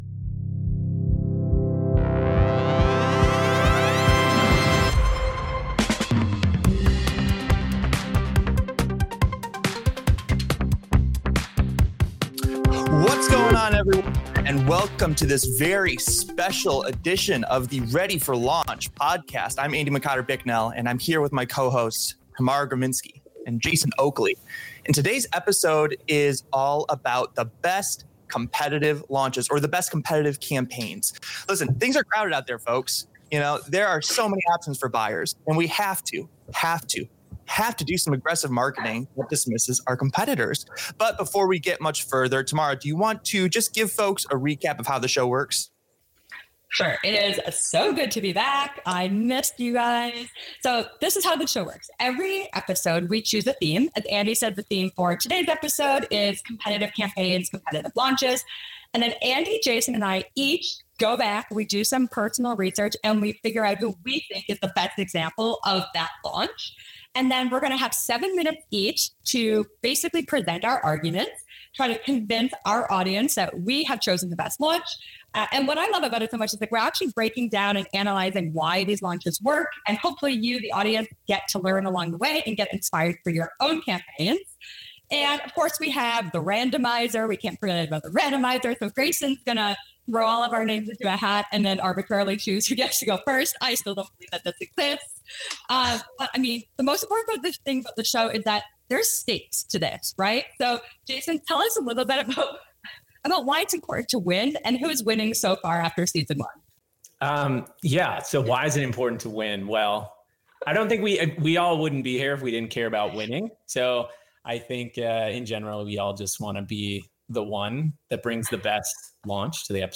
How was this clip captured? The Best Competitive Ad Campaign (LIVE at Compete Week 2024) by Ready for Launch